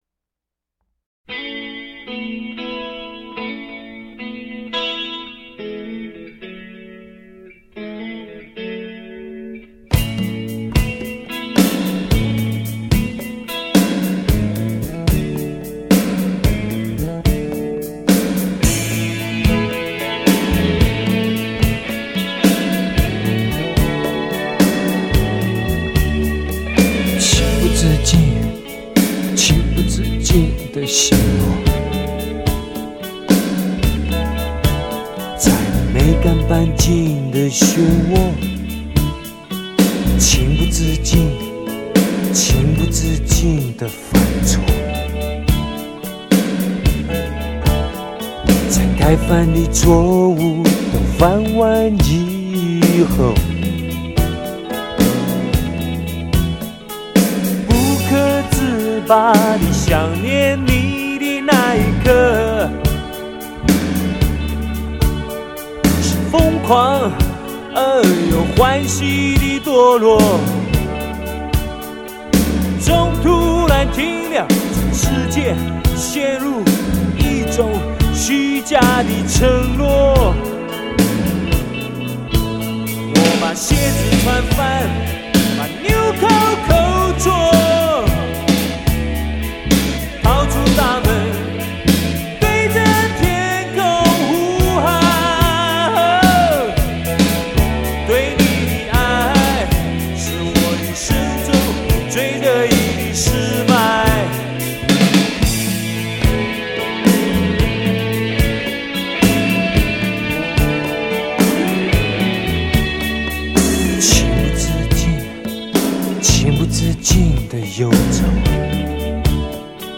本专辑首次发行日为1987年，当年录音技术为类比录音，为尊重当年录音品质，不修饰，不增加声音内容，全部原音重现。
音源采用24Bit/96kHz Remater 数位化处理 AAD
这使得整张专辑更硬、更快。